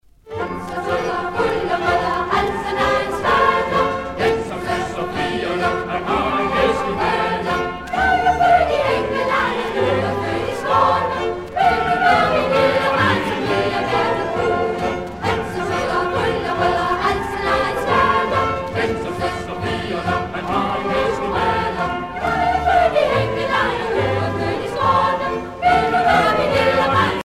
Danske folkesange